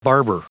Prononciation du mot barber en anglais (fichier audio)
Prononciation du mot : barber